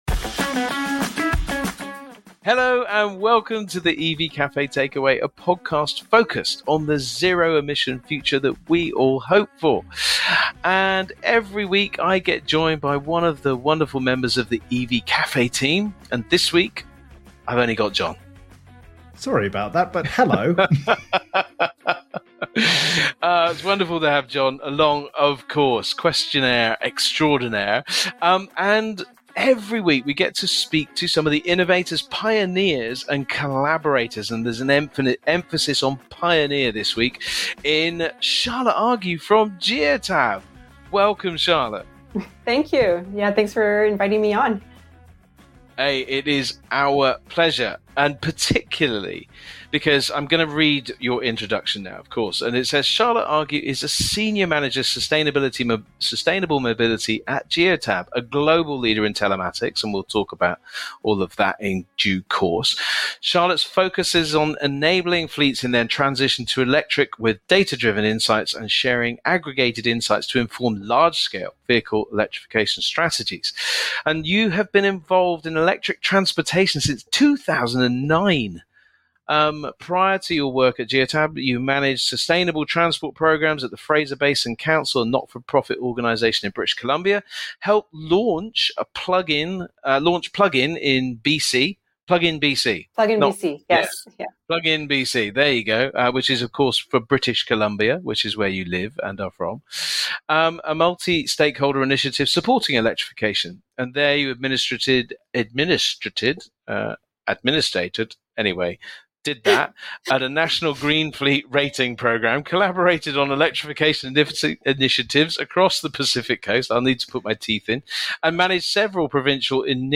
Dive into this thoughtful conversation that bridges continents and ideas, emphasising the strength of unified efforts.